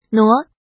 怎么读
nuó
nuo2.mp3